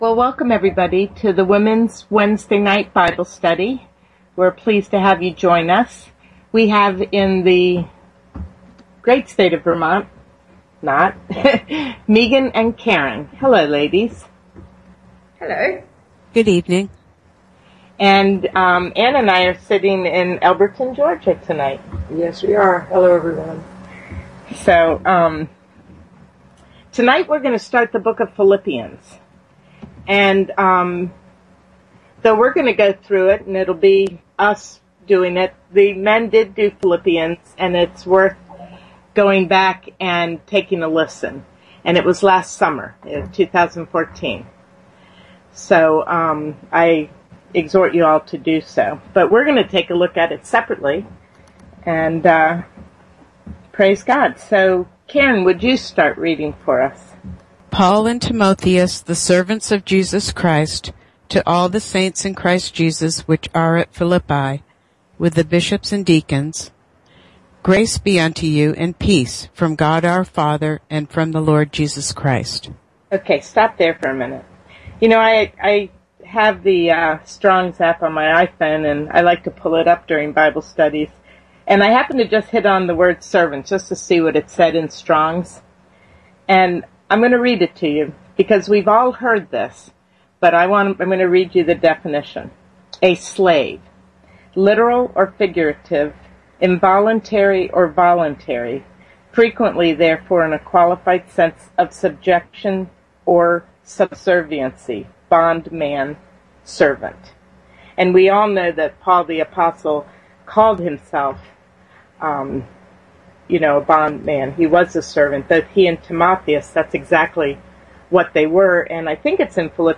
Wednesday Womens Bible Study 4/01/2015 | The Fishermen Ministry